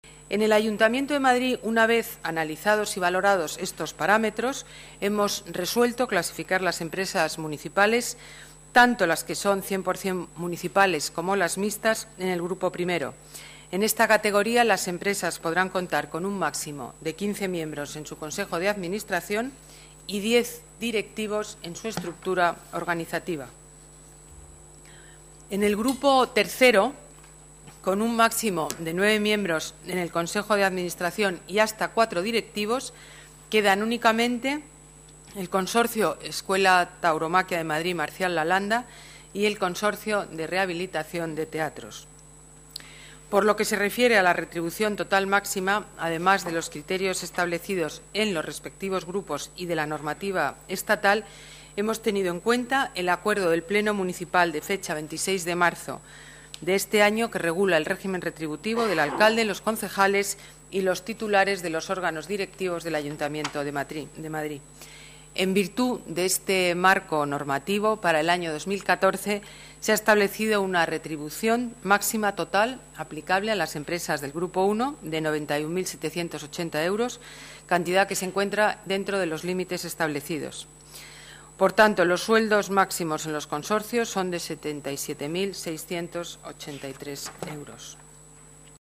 Nueva ventana:Declaraciones alcaldesa Madrid, Ana Botella: regulación empresas municipales sueldos y directivos